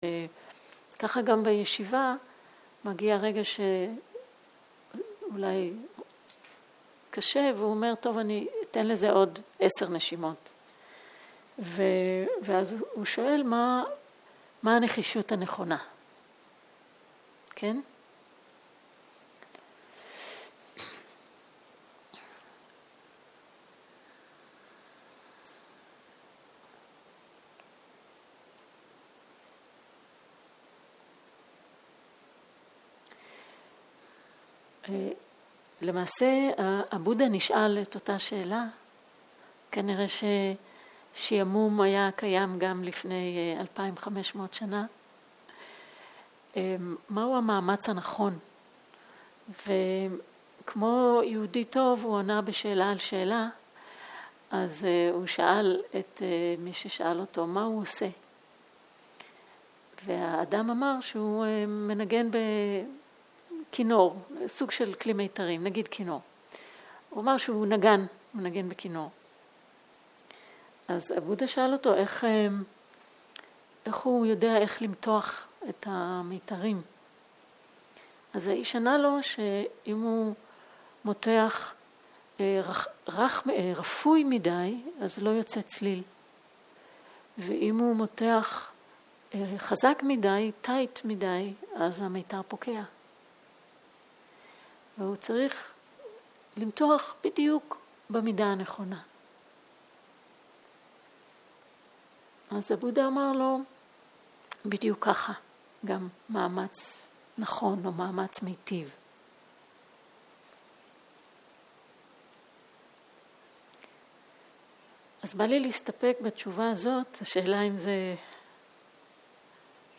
שאלות תשובות
סוג ההקלטה: שאלות ותשובות